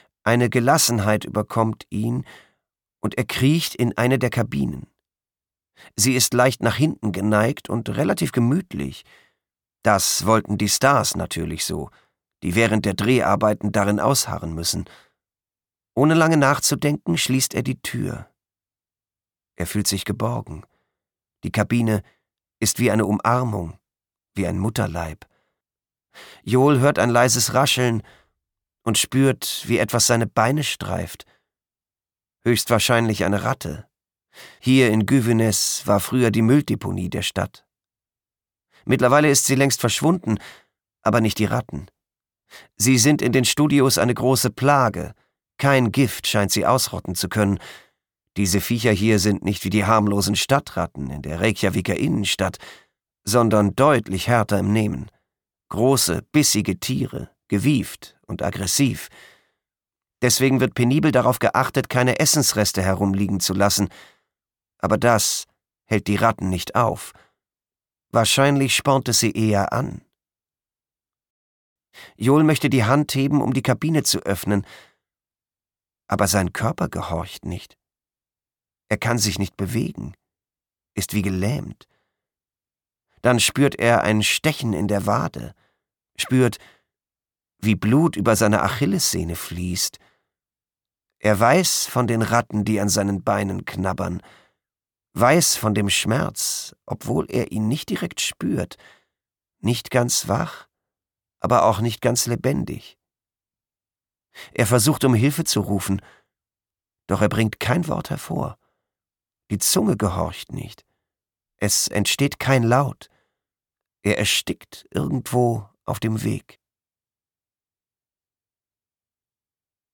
Gift - Jón Atli Jónasson | argon hörbuch
Gekürzt Autorisierte, d.h. von Autor:innen und / oder Verlagen freigegebene, bearbeitete Fassung.